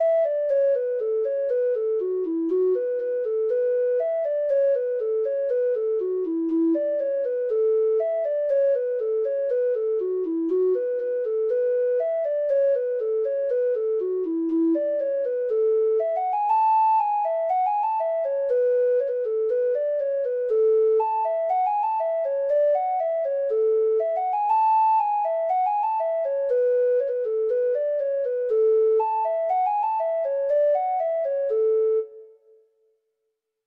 Free Sheet music for Treble Clef Instrument
Reels
Irish